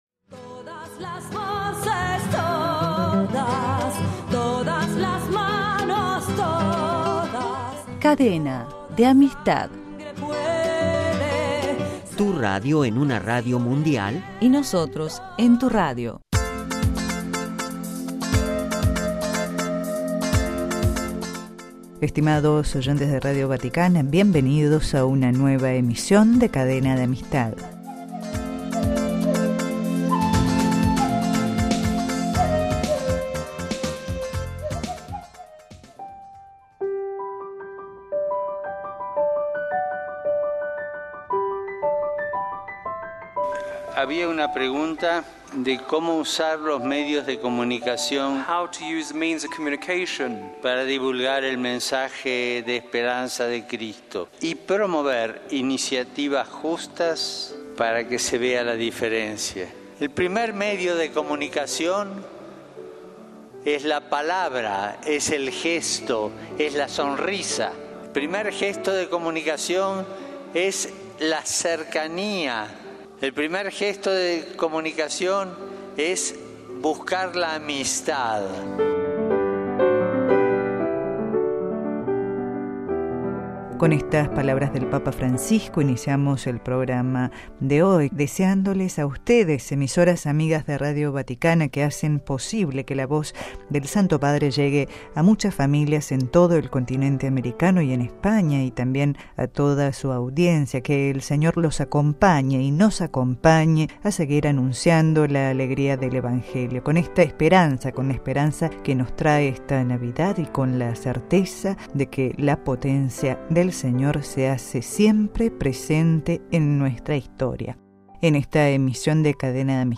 En esta Emisión de Cadena de Amistad, los testimonios de algunas Emisoras amigas que han participado en este espacio de las Radios católicas del continente americano y de España.